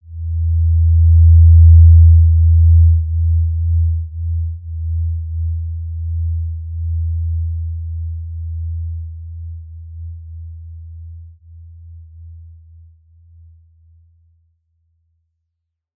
Basic-Tone-E2-mf.wav